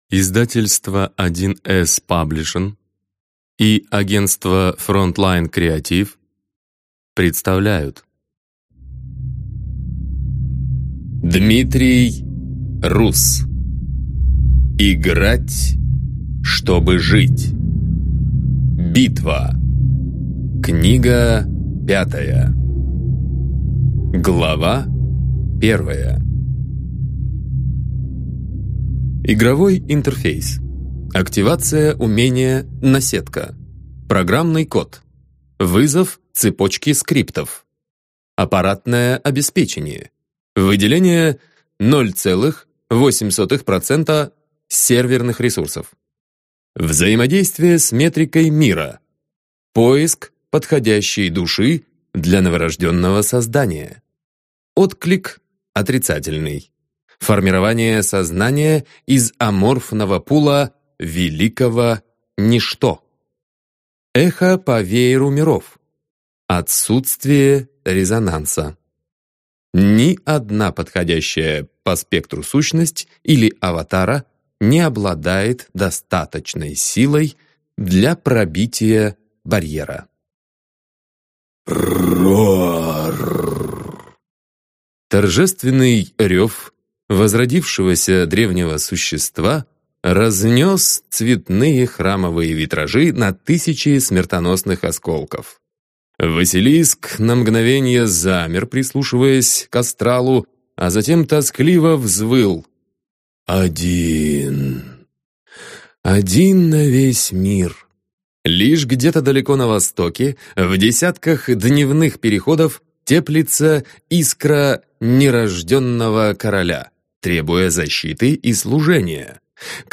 Аудиокнига Играть, чтобы жить. Книга 5. Битва | Библиотека аудиокниг